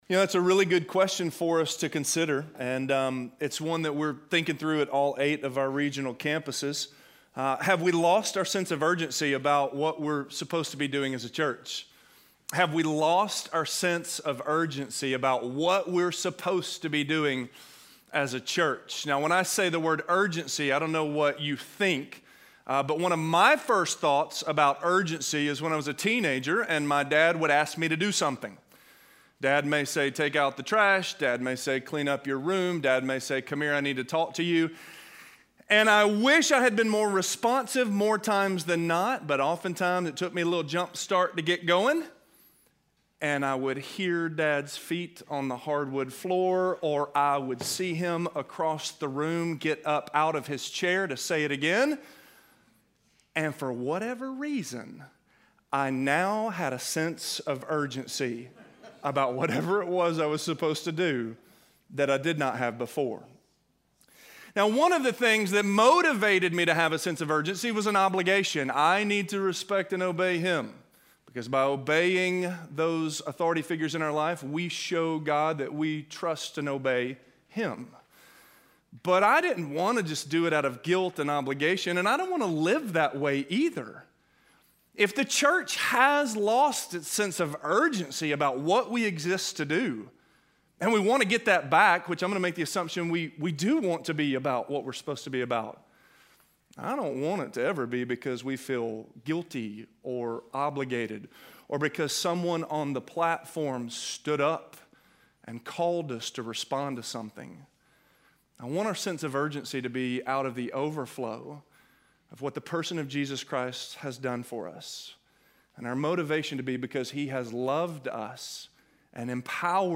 While There Is Still Time - Sermon - Avenue South